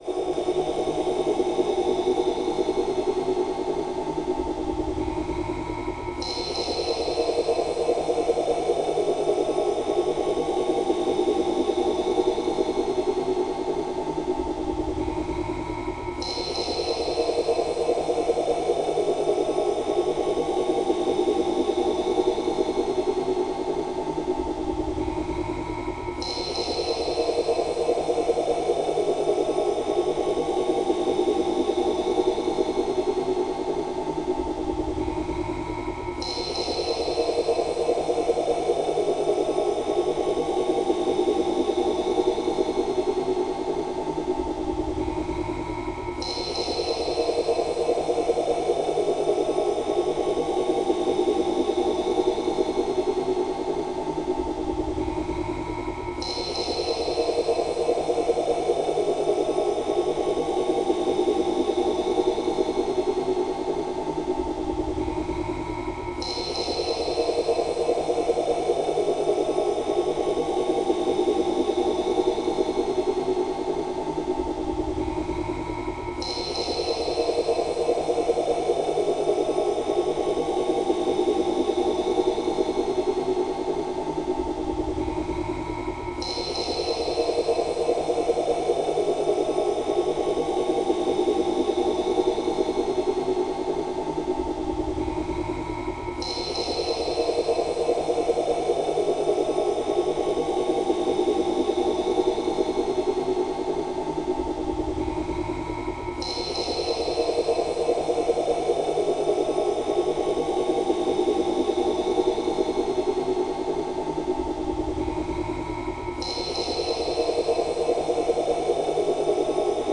Môžete si cez slúchadlá v MP3 prehrávači či priamo v počítači pustiť špecialny zvukový súbor a vyskúšať efekt.
alfa_hladina01.mp3